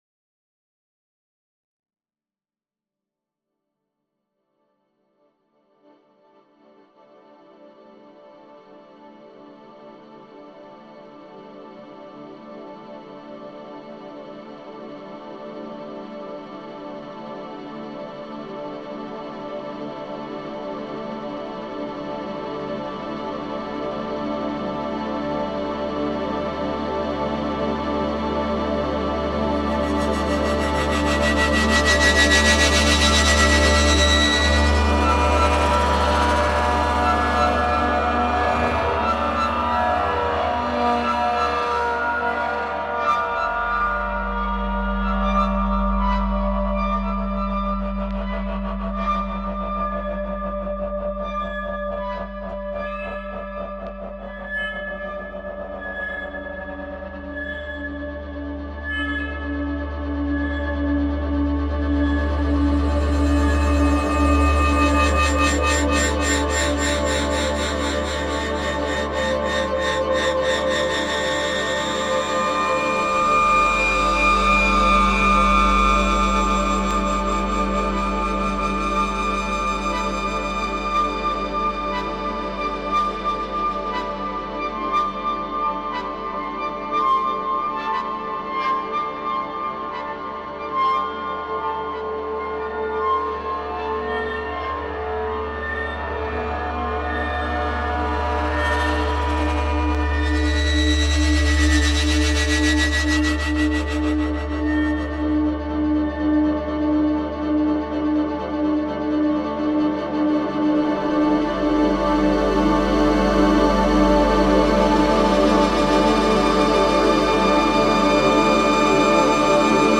Experimental | Field recordings